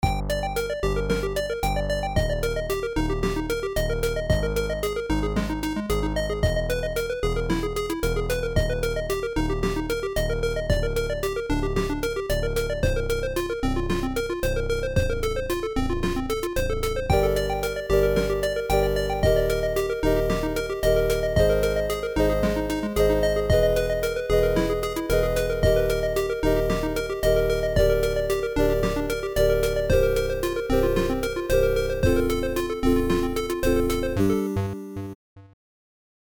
A space theme chiptune.